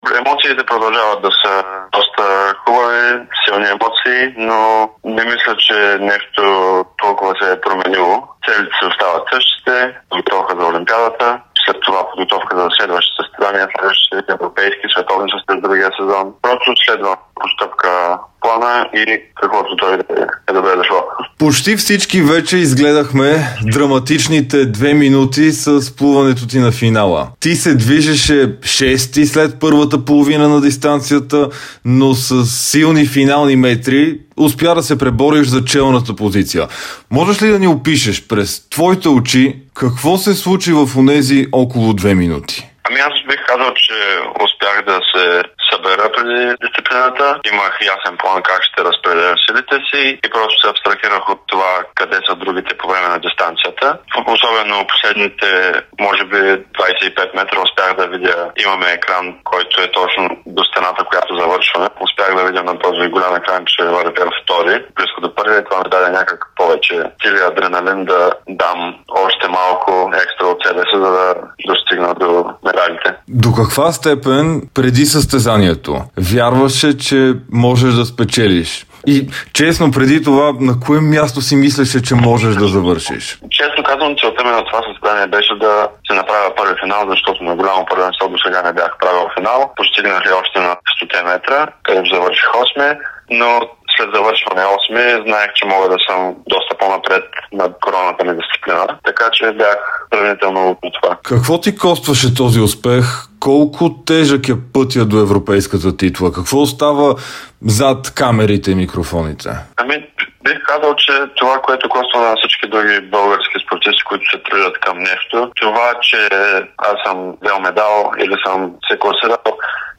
Европейският шампион в дисциплината 200 метра бруст Любомир Епитропов от шампионата на Стария континент в Белград даде ексклузивно интервю пред dsport. Той посвети златния медал на цяла България и призна, че не може да говори за лишения по пътя към триумфа, защото той самият е избрал този начин на живот.